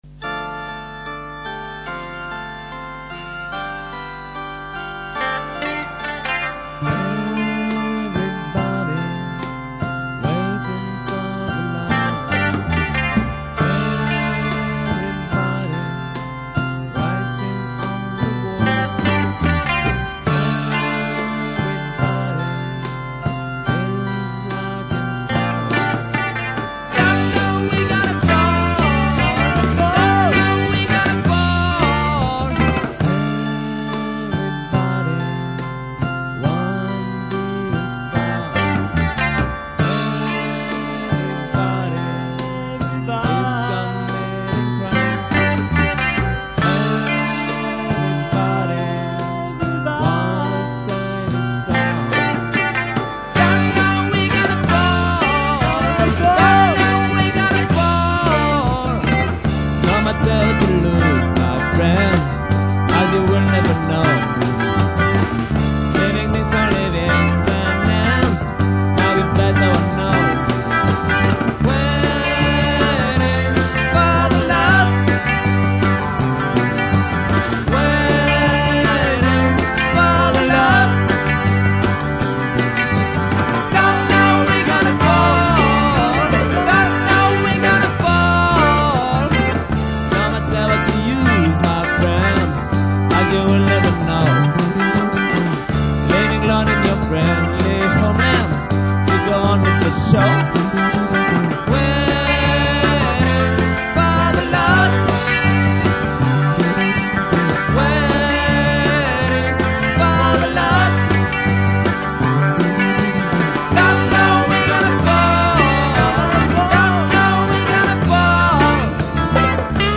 Dans la mouvance naissante du punk rock lyonnais ( Starshooter, Marie et les Garçons, Electric Callas) , nous avons essayé de construire du "New Rock"  (comme on disait) en associant énergie et mélodie.
guitares et chant
basse
claviers
batterie
La qualité sonore est inférieure à l'original du fait de la compression sévère  ( ! ) mais reste acceptable